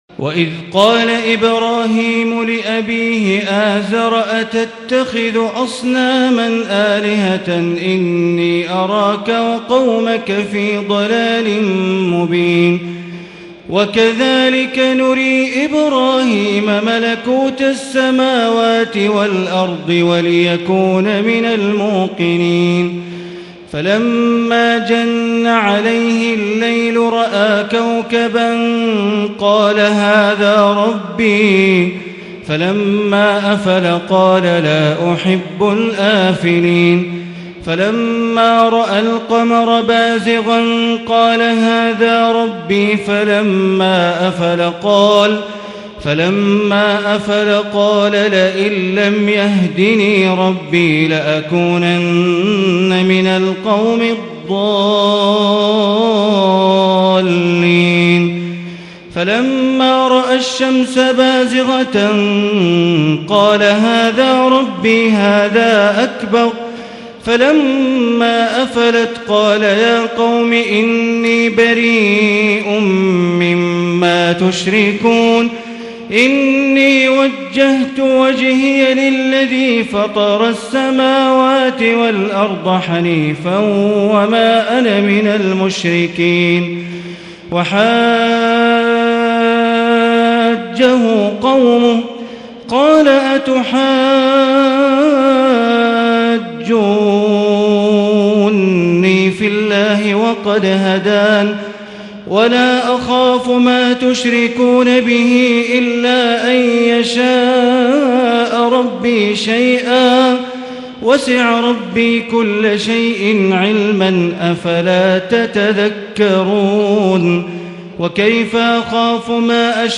تراويح الليلة السابعة رمضان 1440هـ من سورة الأنعام (74-144) Taraweeh 7 st night Ramadan 1440H from Surah Al-An’aam > تراويح الحرم المكي عام 1440 🕋 > التراويح - تلاوات الحرمين